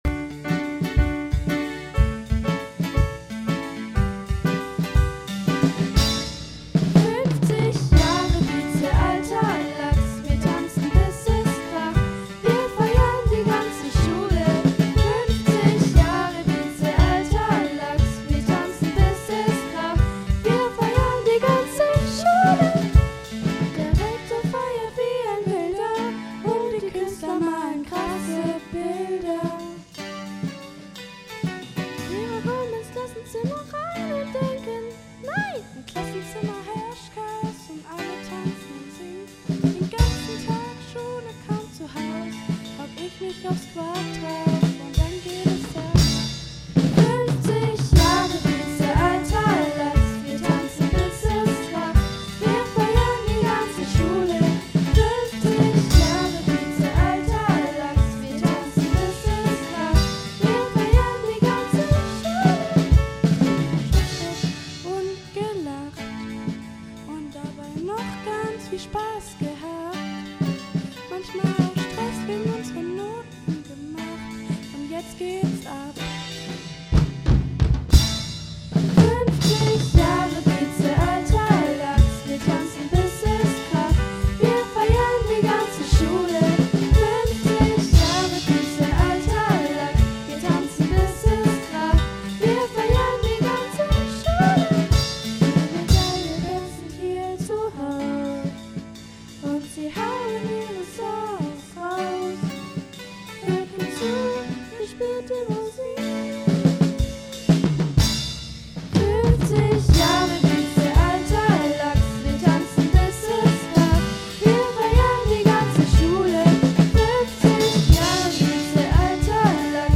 BIZE Schulband - 50 Jahre BIZE.mp3